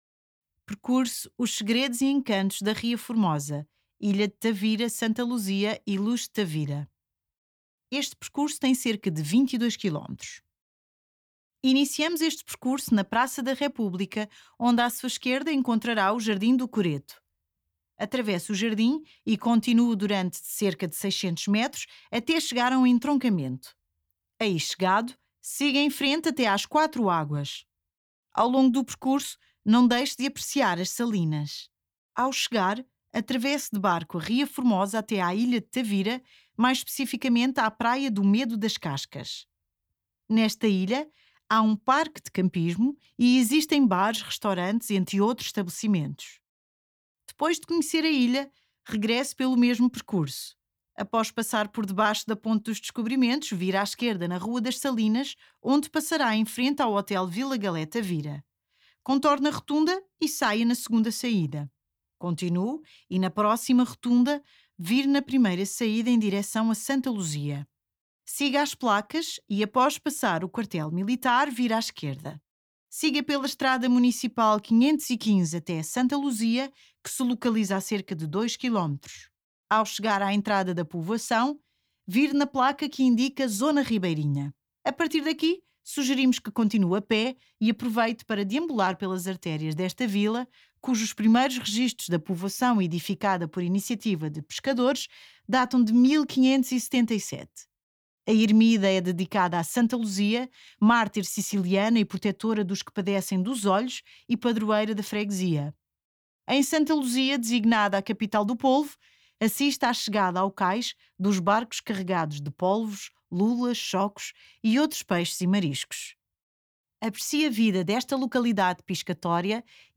Distância 22km Tempo médio 3 horas Principais pontos a visitar Praça da República; Quatro Águas; Cais dos Barcos; Forte do Rato; Mercado Municipal Percurso_Os segredos e encantos da Ria Formosa AudioGuia_Os segredos e encantos da Ria Formosa